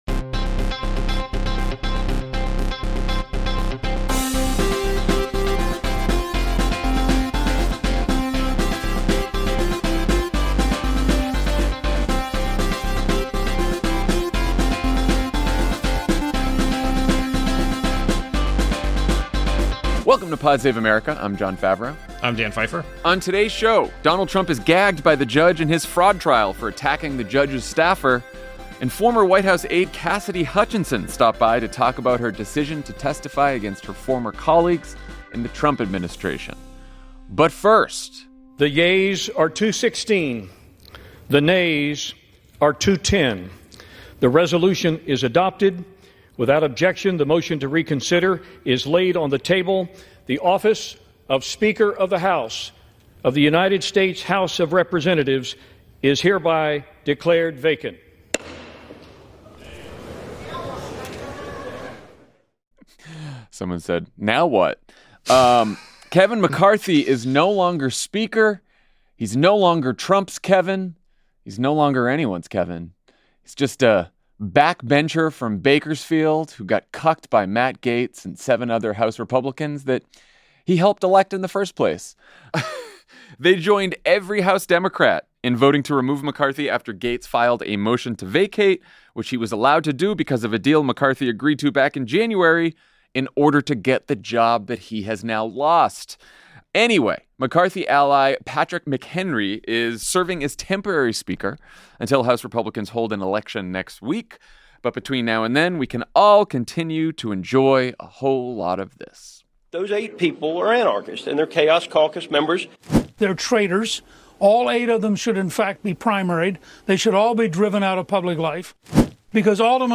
Former Trump White House aide Cassidy Hutchinson joins the show to talk about testifying against her former boss, her new book, "Enough," and the current disarray in the House. Then, Dan and Jon talk about the race for a new Speaker.